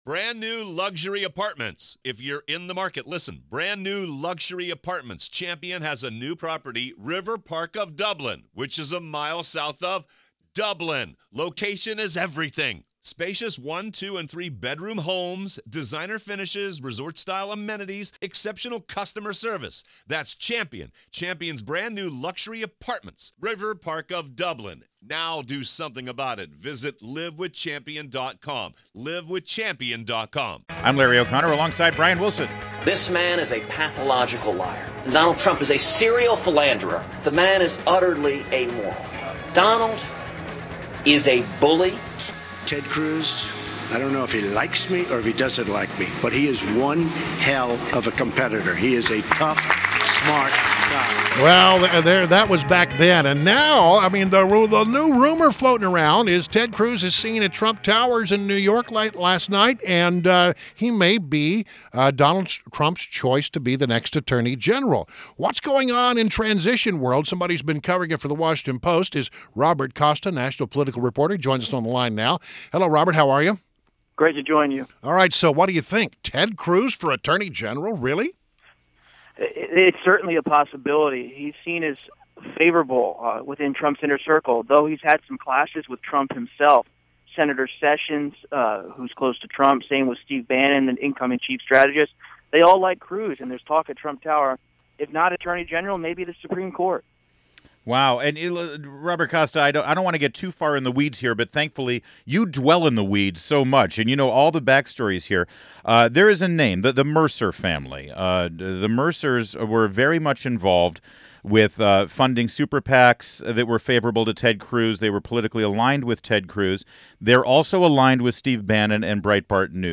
INTERVIEW - ROBERT COSTA - a national political reporter at The Washington Post